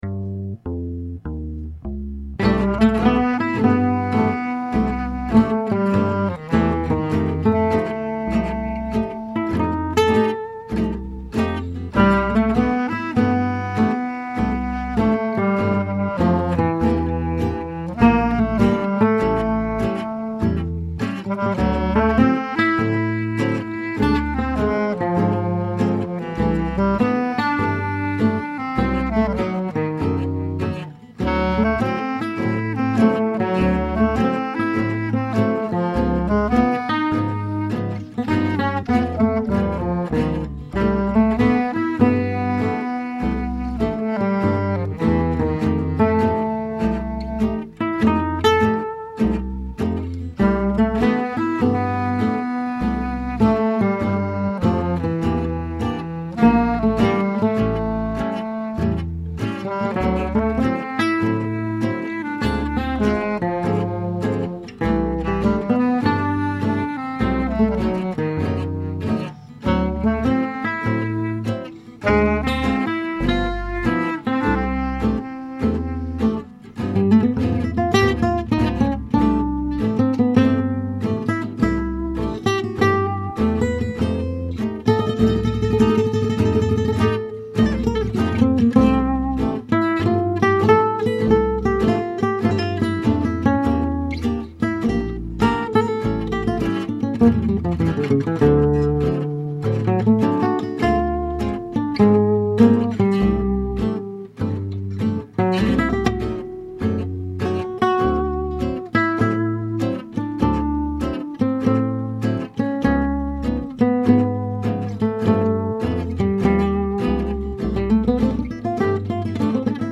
Ça m'a tellement pris la tête que je ne joue plus que des trucs dans les graves, donc sans la clef de 12.